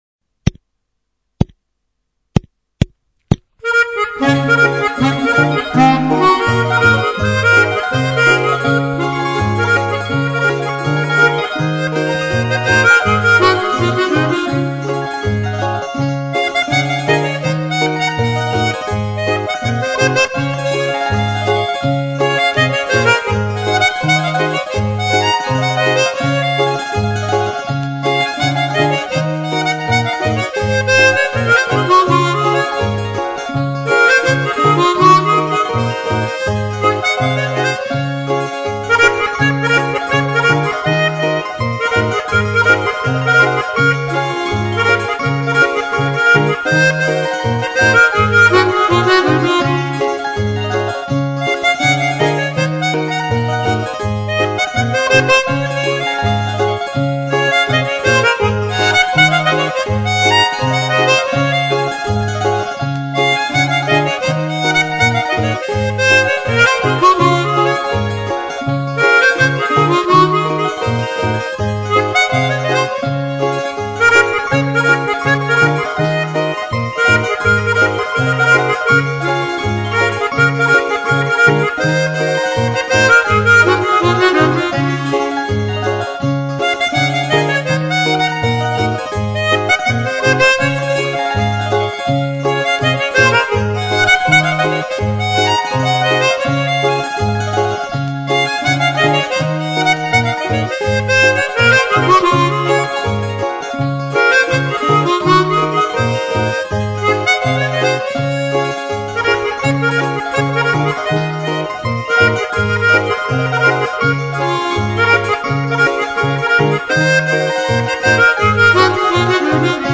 il est vif ,et gai